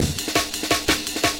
The fundamental technique in manipulating drum breaks like the Amen is to rearrange the slices to get new patterns from the drum loop – below are a series of screenshots with audio examples to show how rearranging the slices can work.